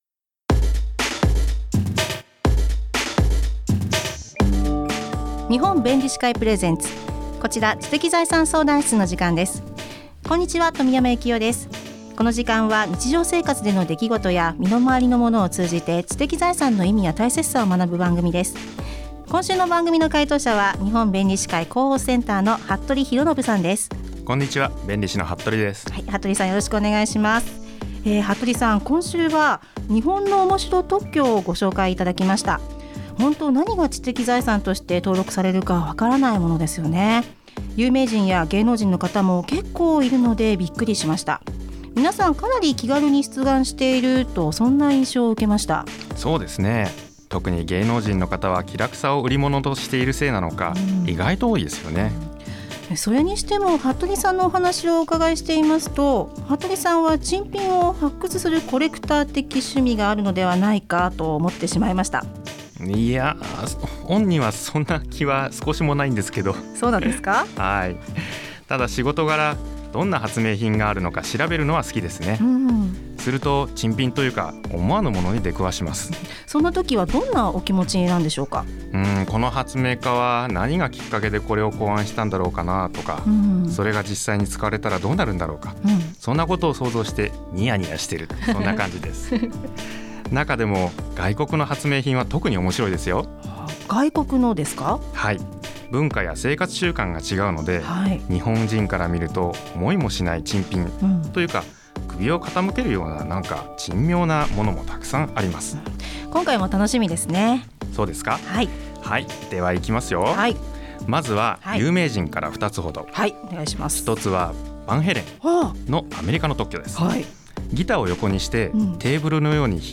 ラジオトーク